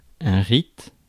Ääntäminen
IPA : /ɹaɪt/